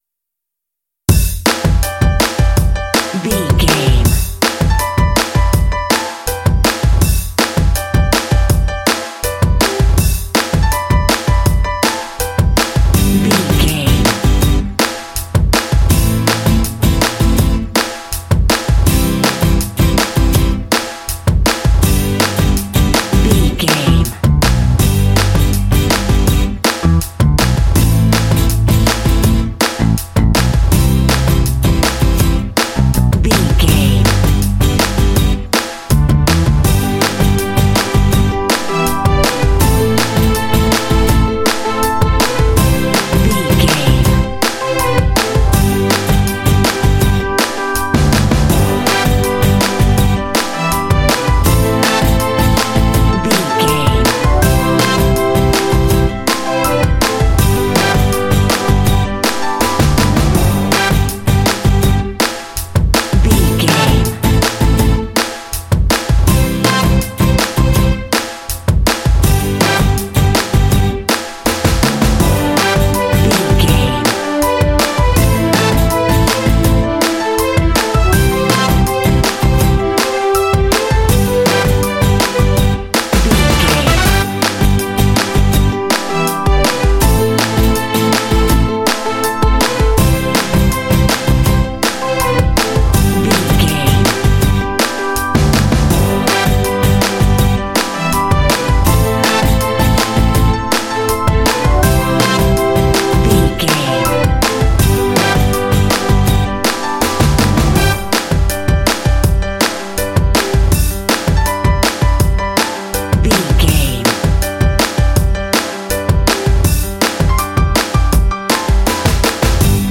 Ionian/Major
bright
uplifting
piano
drums
acoustic guitar
strings
bass guitar
pop
contemporary underscore